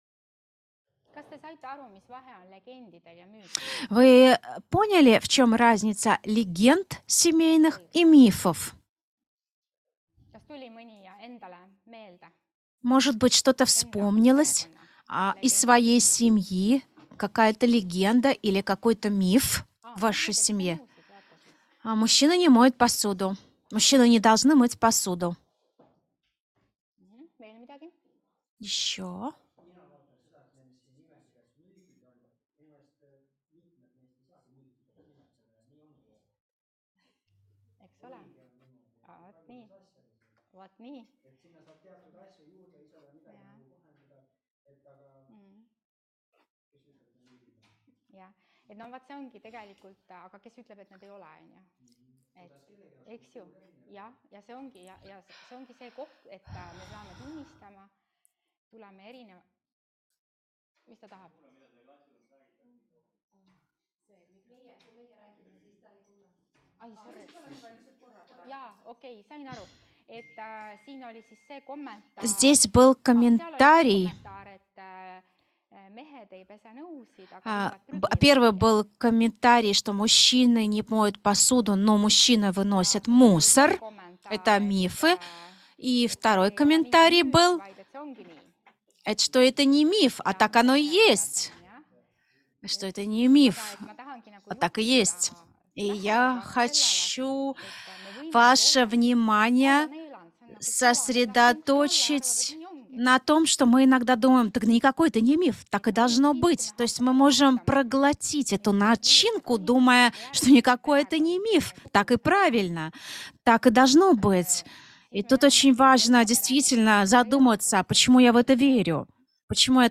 Семейное консультирование. 4. лекция [RU] – EMKTS õppevaramu